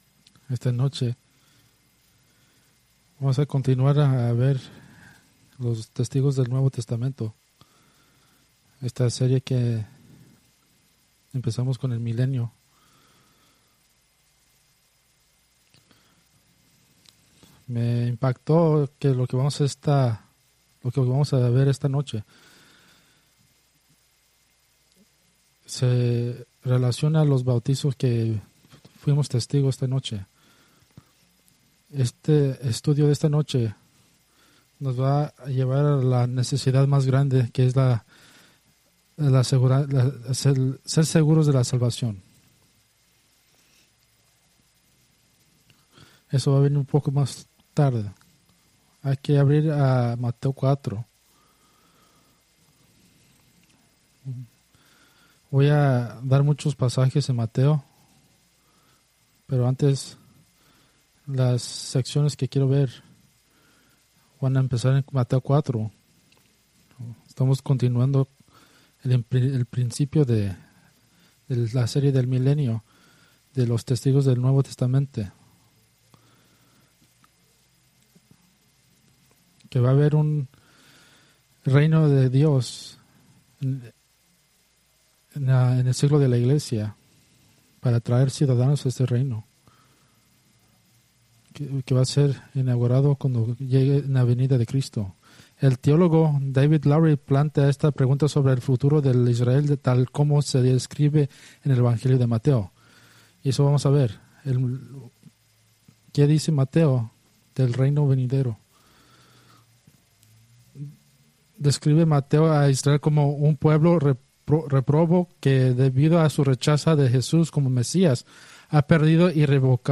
Preached October 20, 2024 from Escrituras seleccionadas